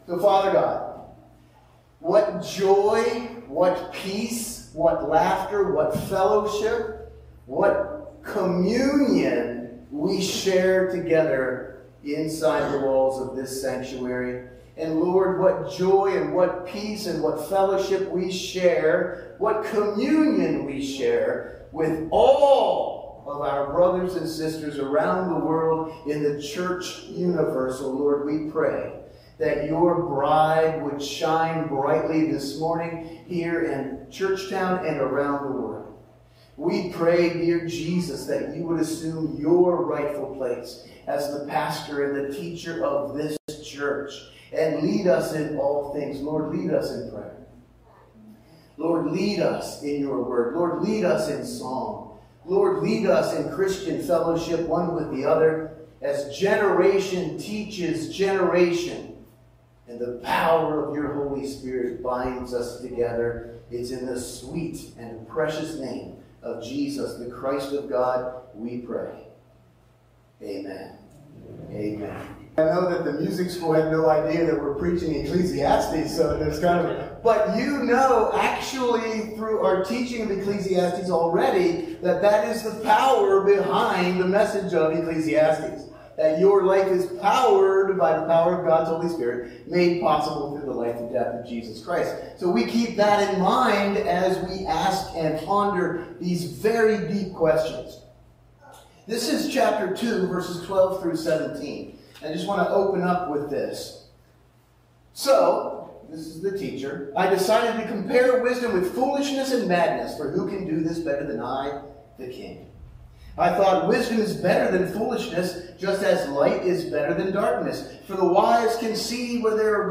Sunday Morning Service – July 28, 2024